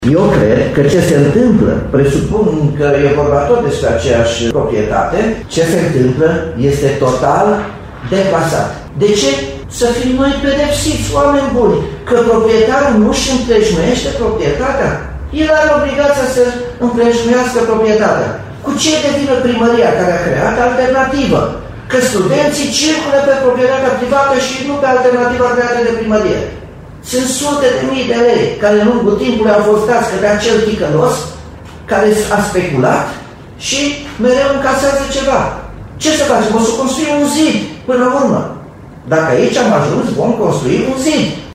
Primarul Nicolae Robu a explicat care ar putea fi soluția de rezolvare a problemei din Complex.